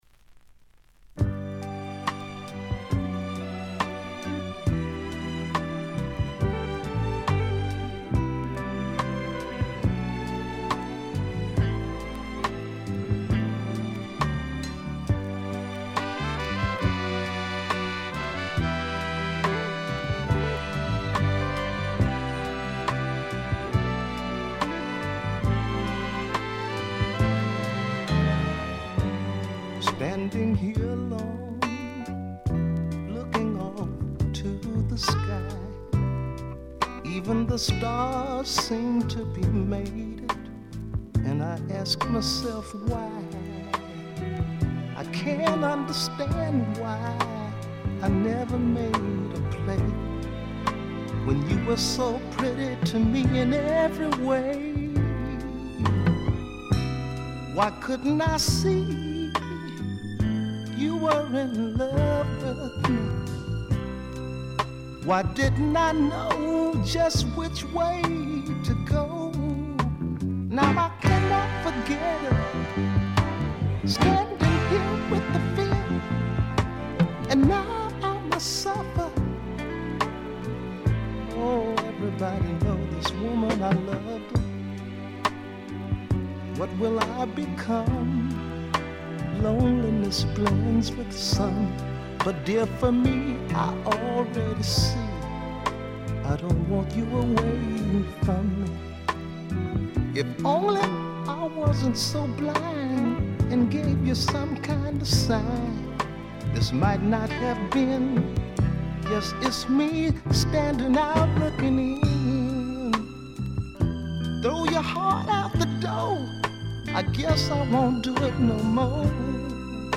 静音部での微細なバックグラウンドノイズ程度。
試聴曲は現品からの取り込み音源です。